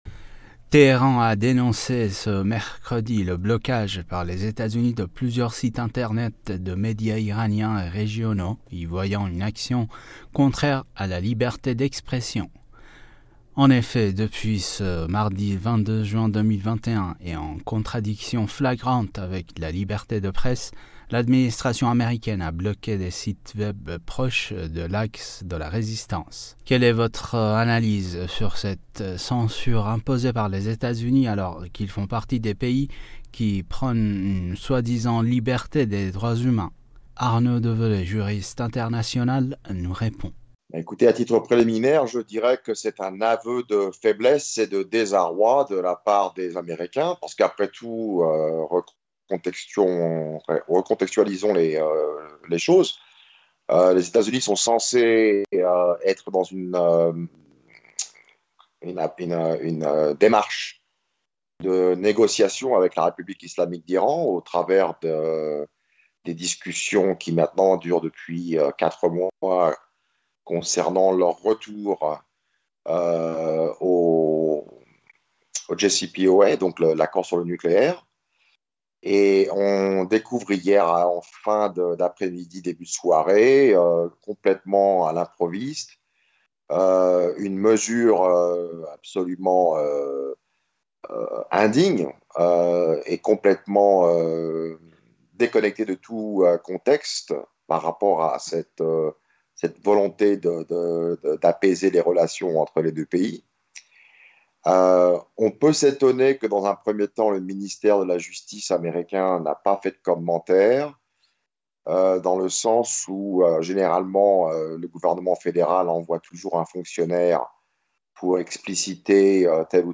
» Mots clés Iran usa Présidentielle iranienne interview Eléments connexes Trump : pourquoi veut-il faire croire ses mensonges sur l’Iran au monde ?